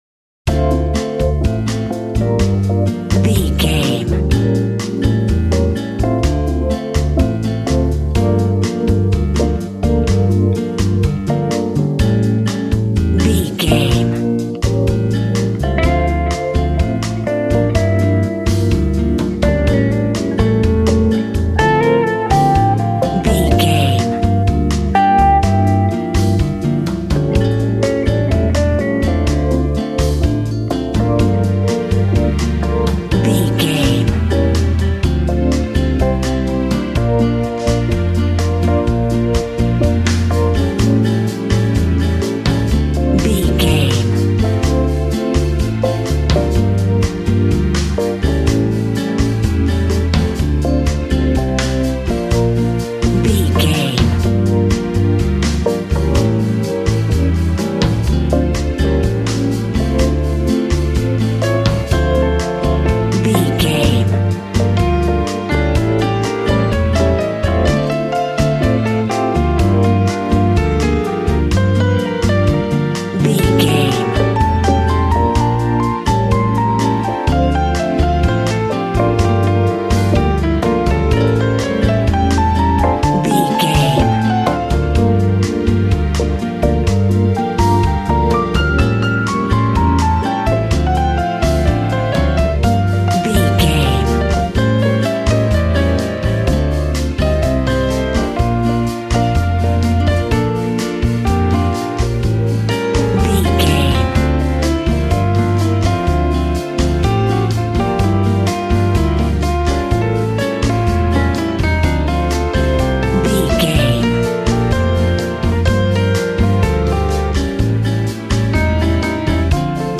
Aeolian/Minor
funky
energetic
romantic
percussion
electric guitar
acoustic guitar